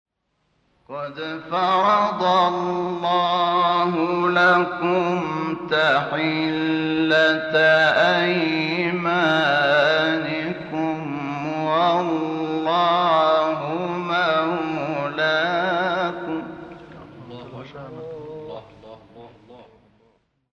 شحات انور- بیات حسینی -سایت سلیم 2014 (1).mp3